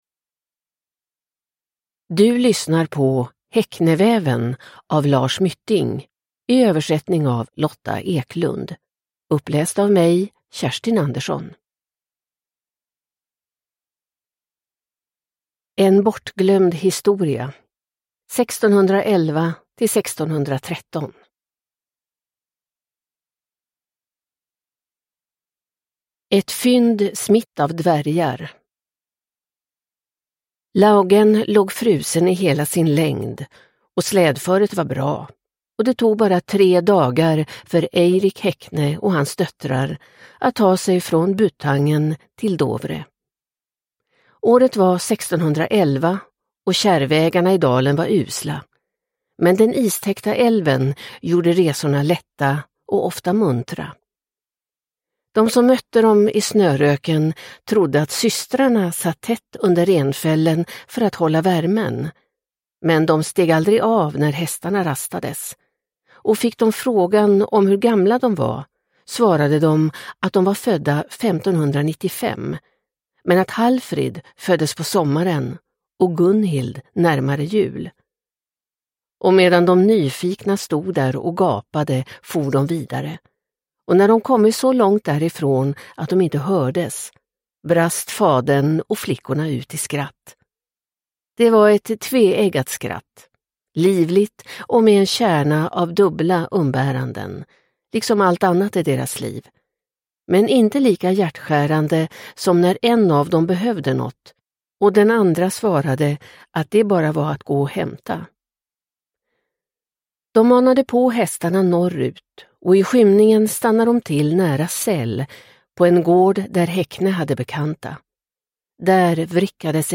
Hekneväven – Ljudbok – Laddas ner